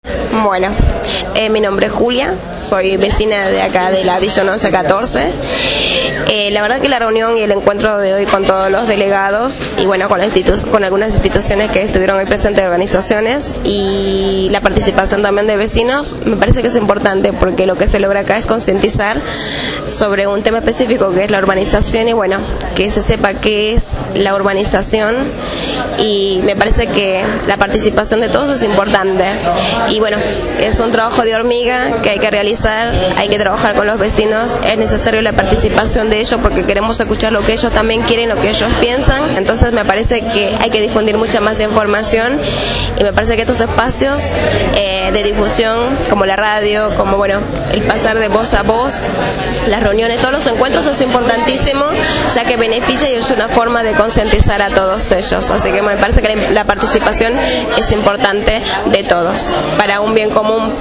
El pasado sábado 12 de noviembre al mediodía se realizó la Jornada de Reflexión «Urbanización Participativa y Organización Popular» realizada en la Iglesia La Esperanza de la villa 1-11-14, Bajo Flores, Comuna 7.
Radio Gráfica estuvo presente y entrevistó al término de la Jornada a vecinos y referentes.